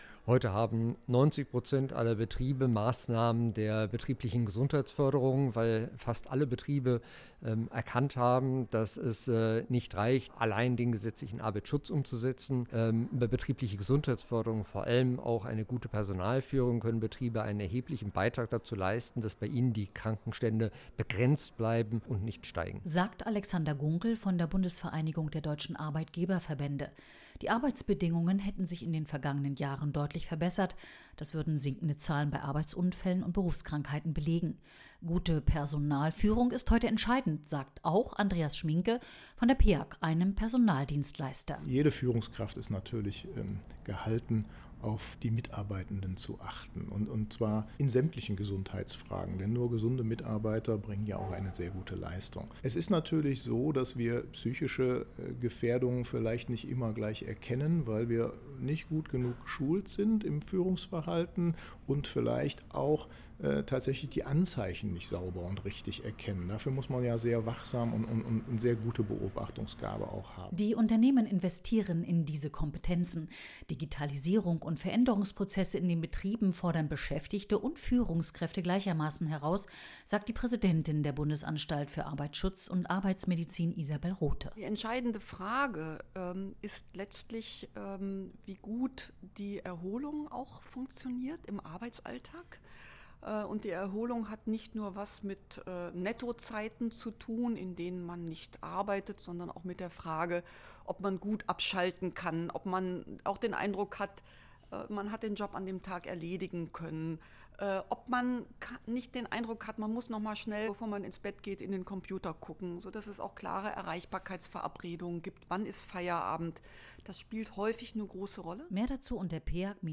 Für die Personaldebatten produzieren wir jeweils Presseinfos, O-Töne und einen sendefertigen Radiobeitrag zum kostenfreien Download.